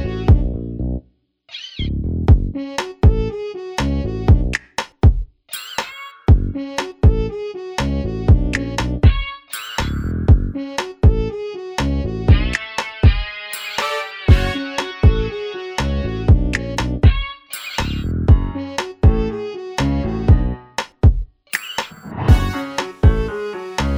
no Backing Vocals R'n'B / Hip Hop 5:00 Buy £1.50